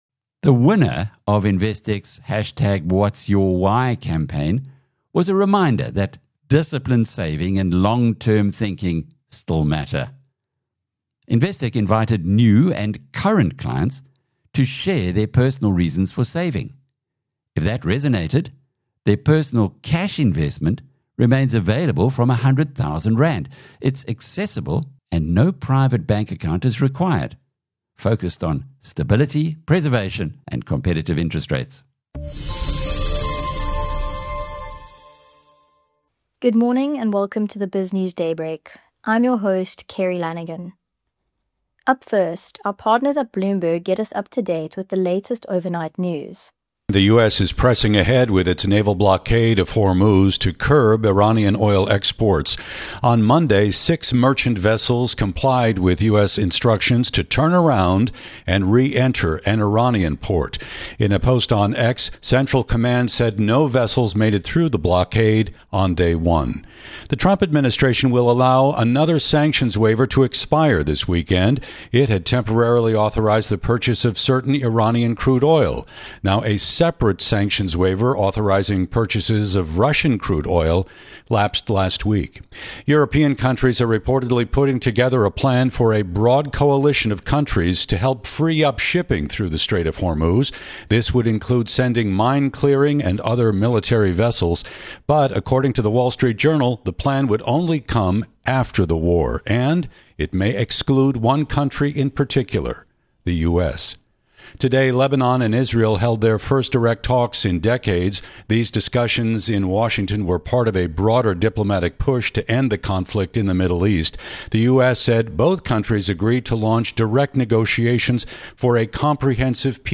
In today's BizNews Daybreak, we dive into the US naval blockade of the Strait of Hormuz and the looming threat of renewed trade tariffs. We explore massive tech moves, from Meta’s multi-billion-dollar AI chip partnership with Broadcom to Amazon’s $11.6 billion acquisition of Globalstar. Locally, we hear from DA leader Geordin Hill-Lewis on ANC succession risks, while economist Nouriel Roubini warns that escalating Middle East tensions could trigger 1970s-style stagflation.